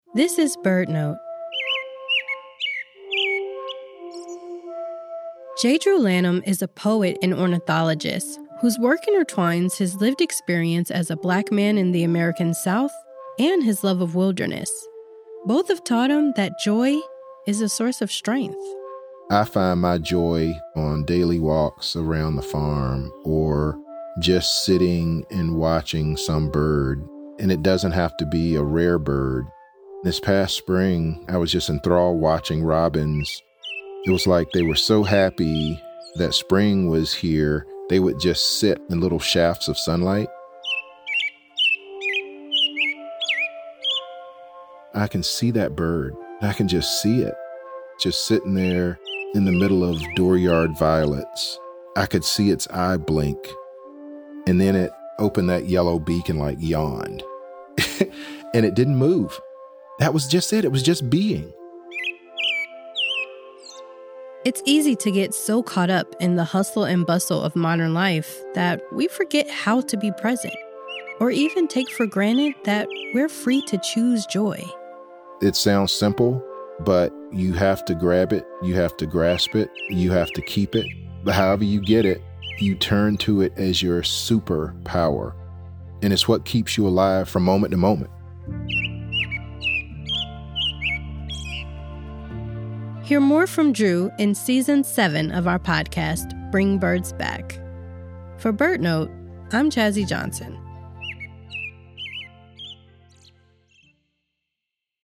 Both have taught him that joy is a source of strength. On Bring Birds Back, Drew describes how he finds radical joy in spending time with birds like the American Robins.